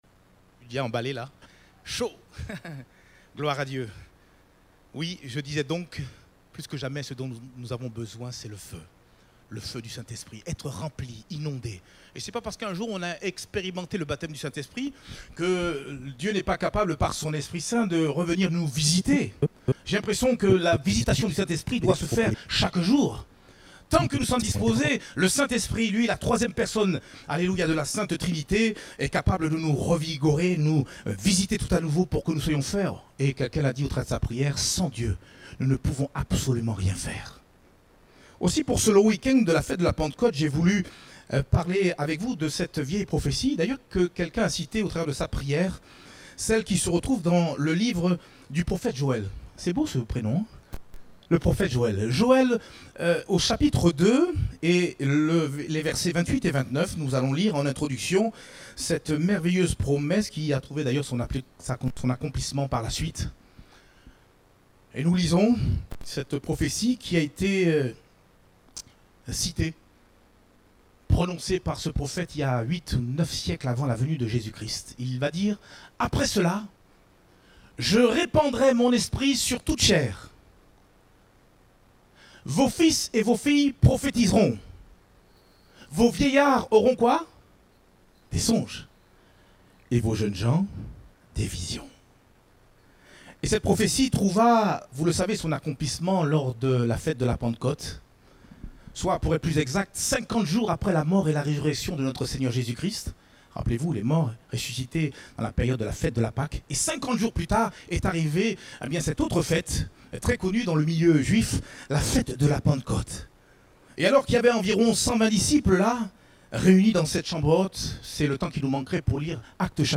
Date : 23 mai 2021 (Culte Dominical)